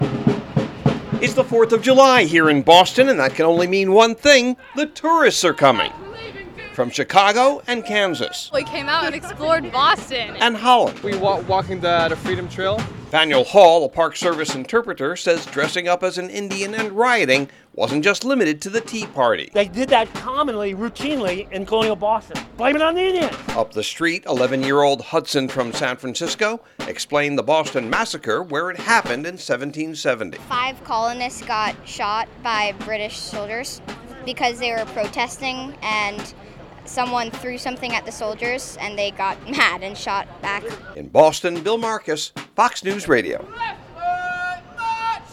AUDIO POST CARD